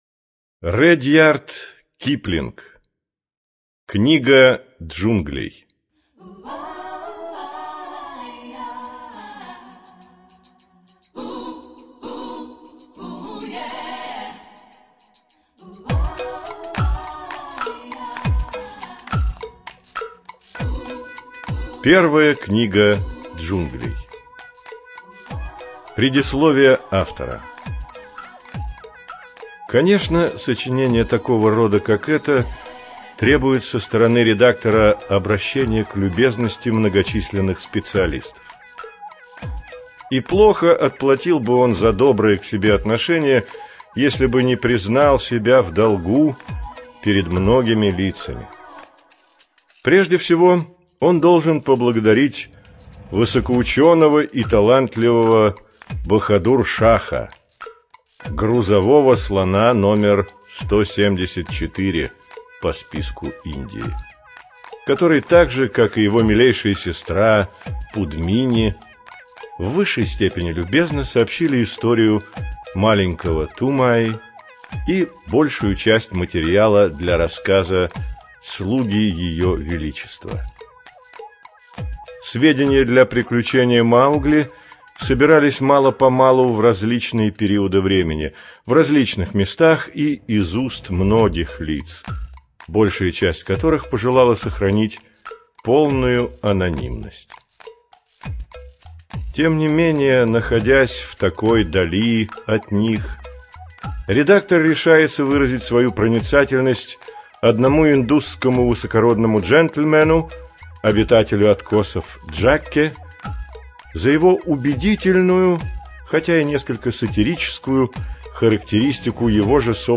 ЖанрДетская литература
Студия звукозаписиБиблиофоника